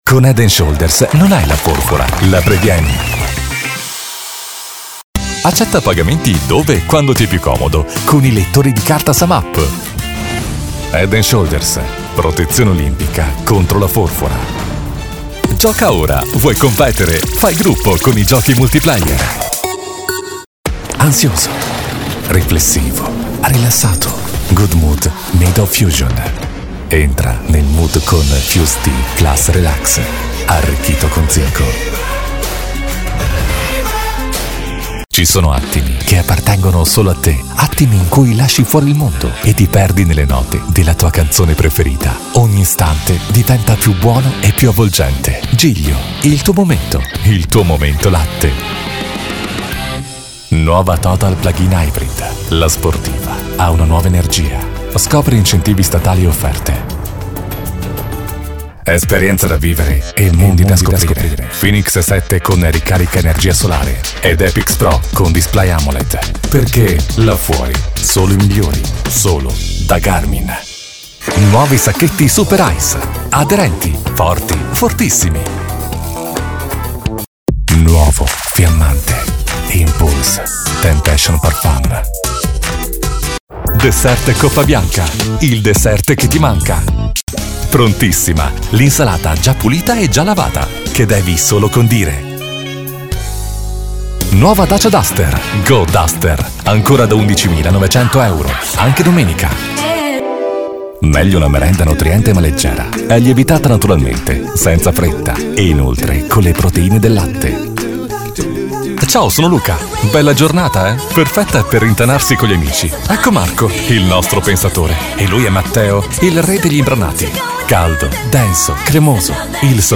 Mi voz es joven y versátil...
Cálido
Elegante
Dinámica